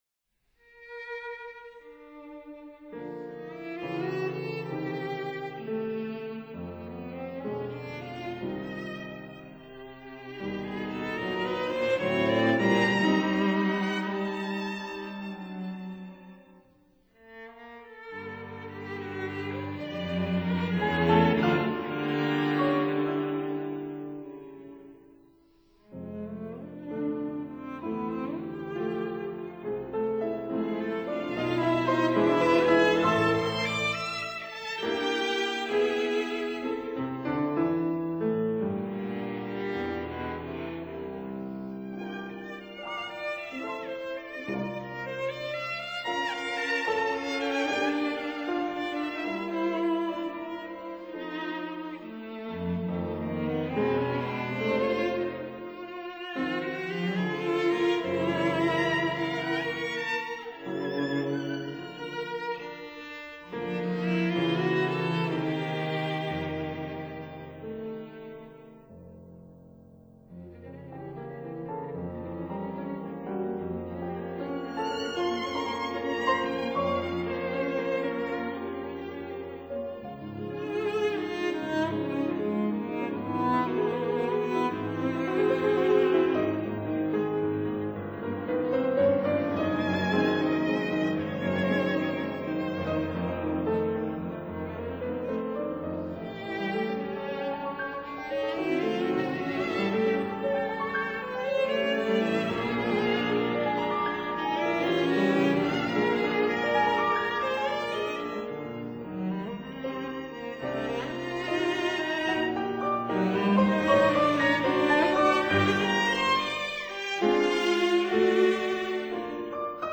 for violin, cello & piano
violin
viola
cello